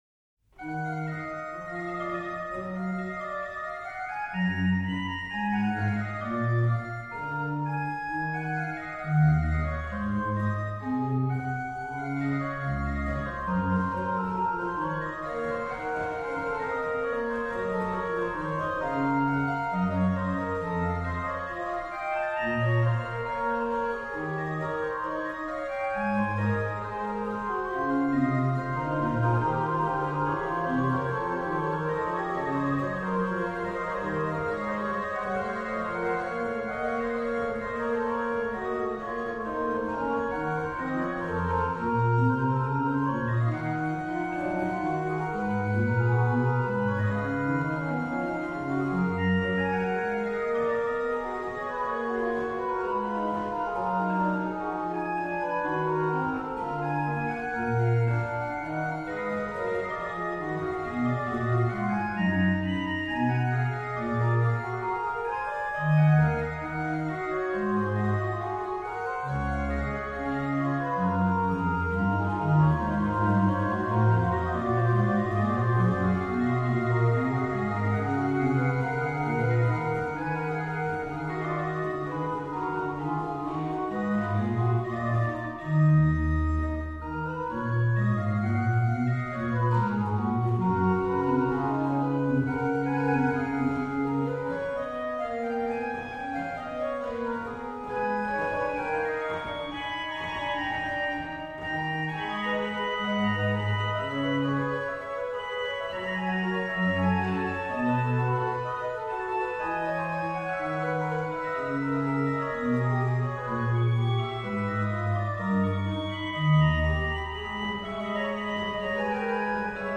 rh: BW: Ged8, Fl4, Nas3
lh: HW: Viol8, Por8, Qnt8, Rfl4
Ped: Oct8